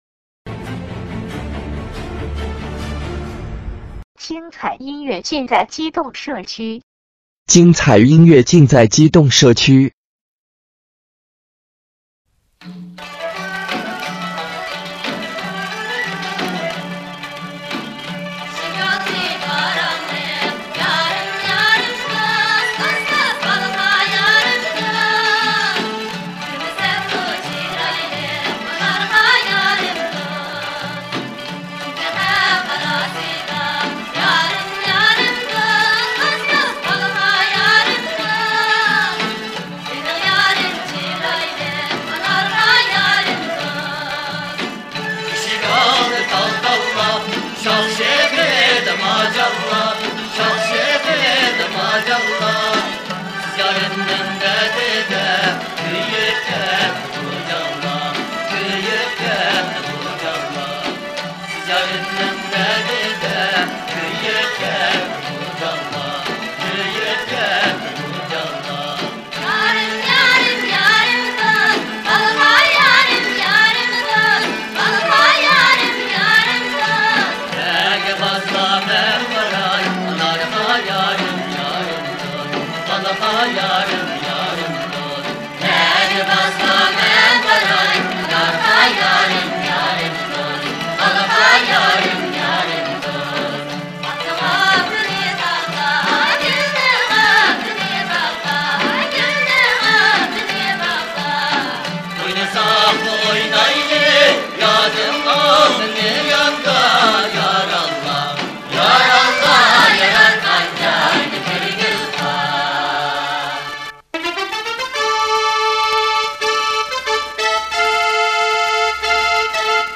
纯正的新疆民歌
样本格式    : 44.100 Hz; 16 Bit; 立体声